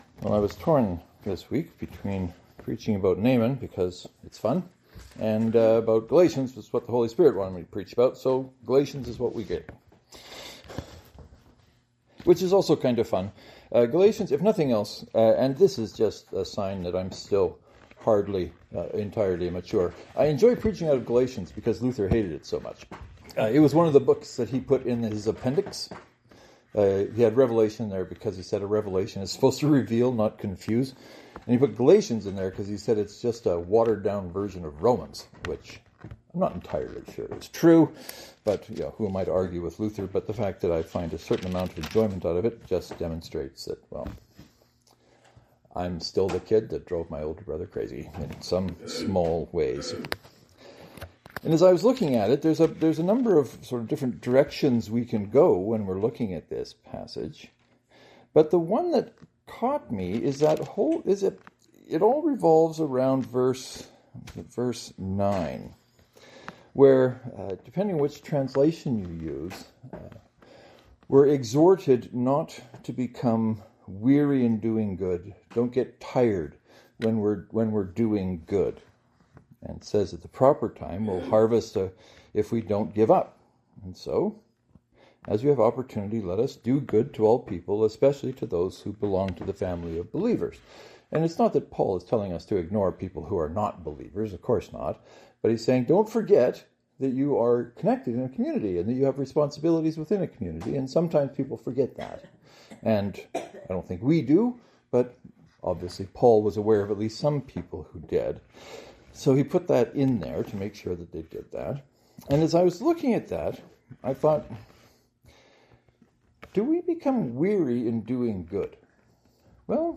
It was these thoughts that led to this week’s sermon.